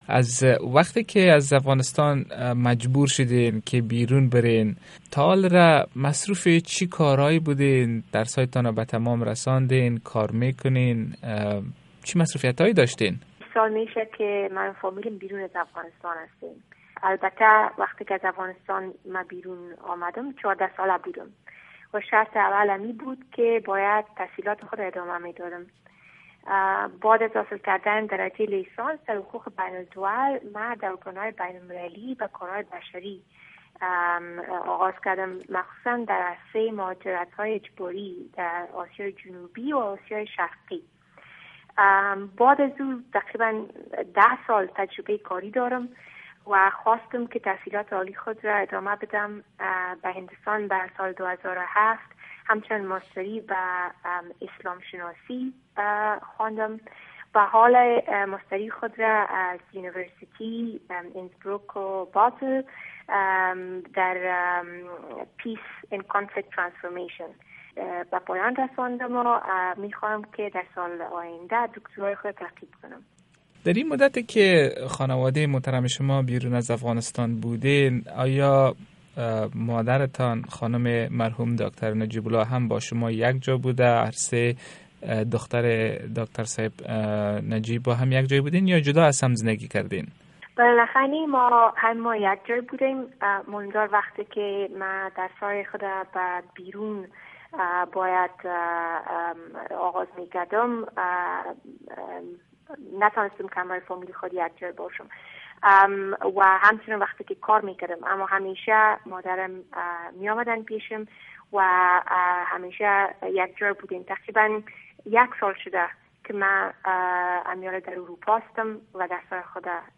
مصاحبۀ